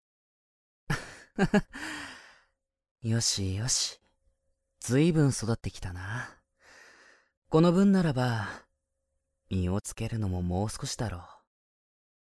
【サンプルセリフ】
雰囲気的には朴訥な感じなのにきりっとした敬語という、少し不思議なタイプになりました。